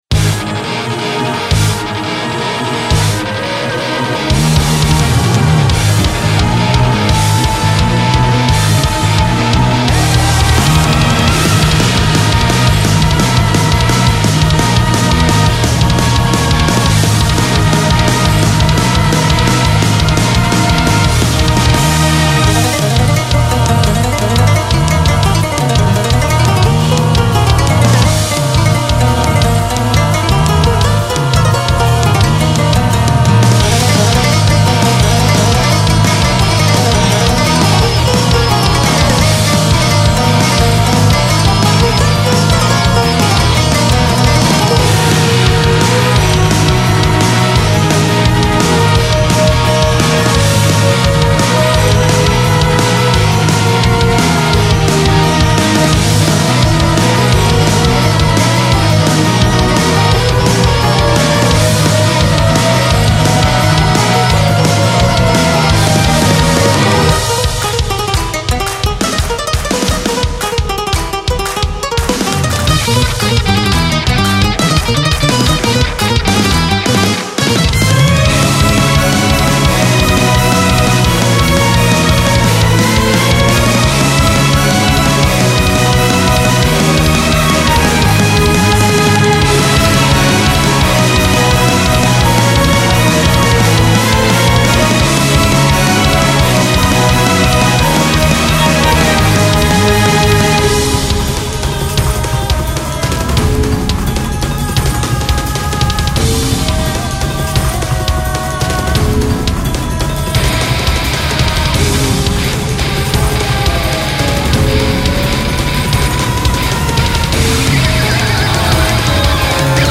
ジャンルロック、エスニック
BPM１７２
使用楽器エレキギター、ガットギター、シタール、ヴァイオリン、ボイス、中東の楽器
解説アラブ世界の雰囲気を帯びた戦闘曲フリーBGMです。
エスニックな楽器や音階を数多く取り入れ、中東風の演出を施しております。